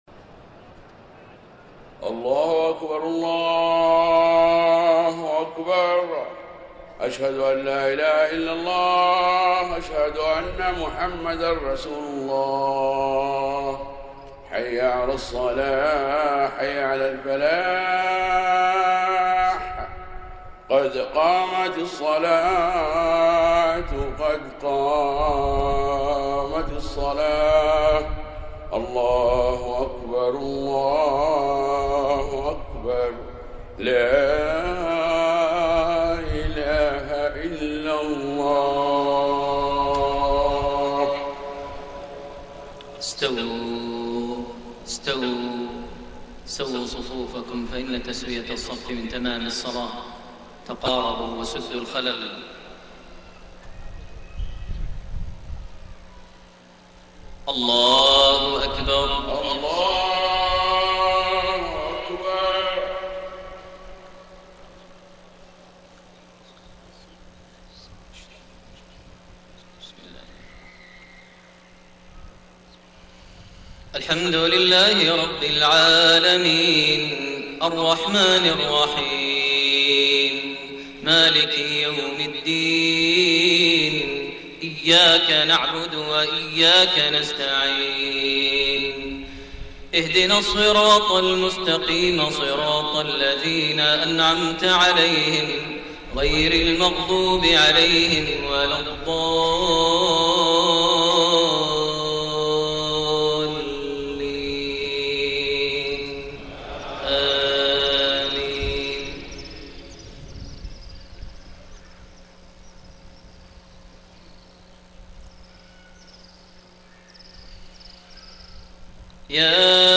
صلاة المغرب 21 صفر 1431هـ خواتيم سورة التحريم 6-12 > 1431 🕋 > الفروض - تلاوات الحرمين